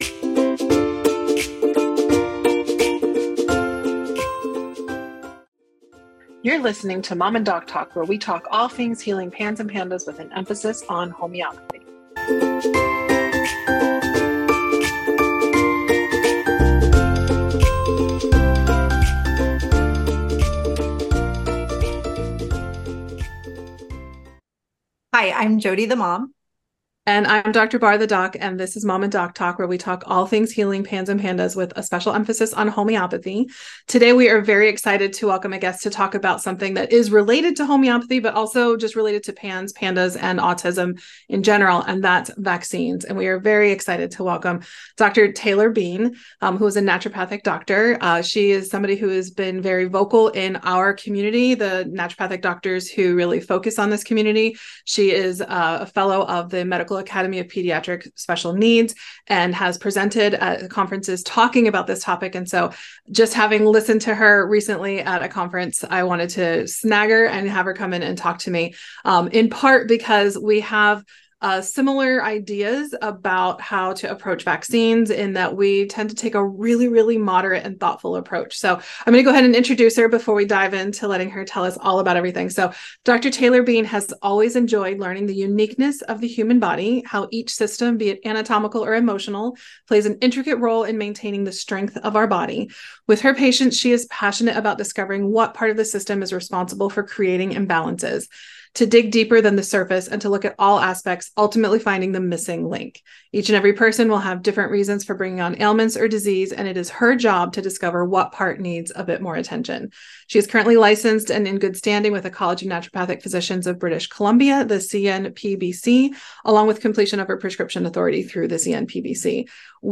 This is an educational conversation. Nothing shared in this podcast should be considered medical or treatment advice.